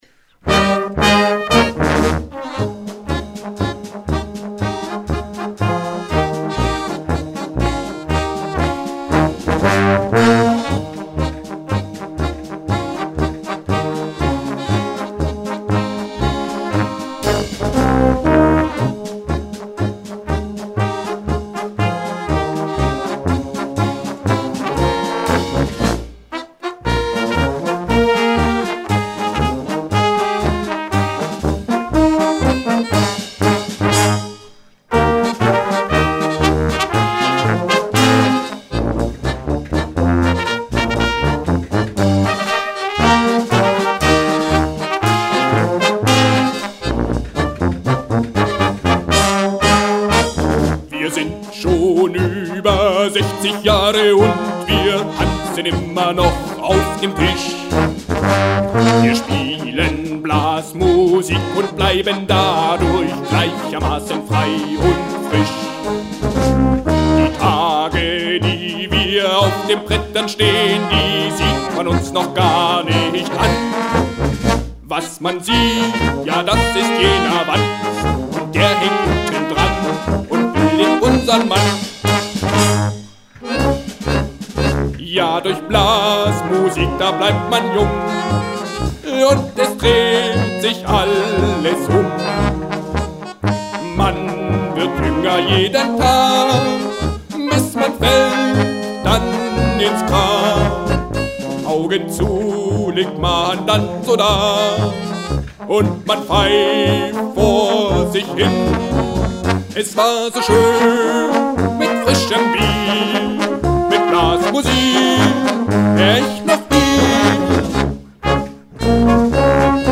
Beispiel für Ihr Schunkeltraining